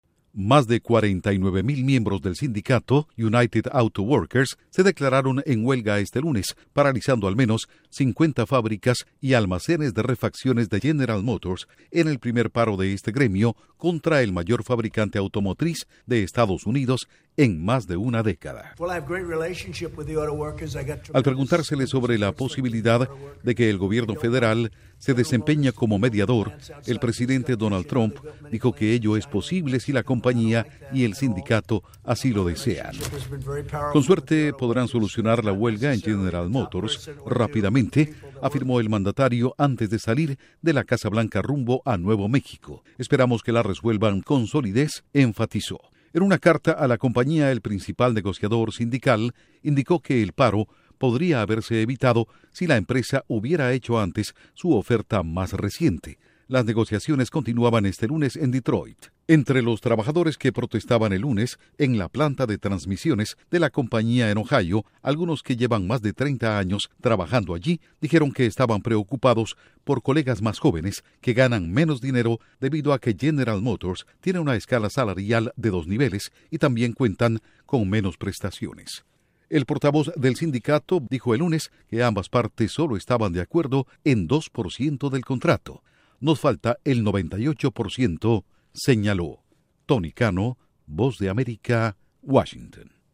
Huelga paraliza múltiples instalaciones de General Motors en Estados Unidos. Informa desde la Voz de América en Washington